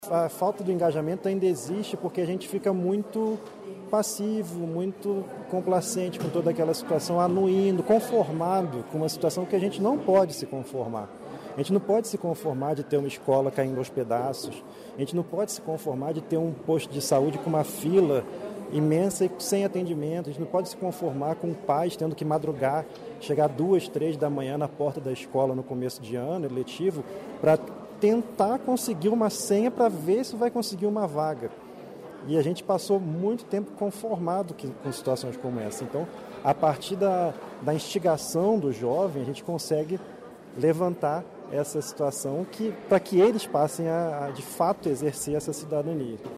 O promotor de Justiça Rodrigo Baptista Braziliano provocou os jovens a assumirem a responsabilidade como cidadãos conscientes de seu papel na sociedade. Confira a entrevista.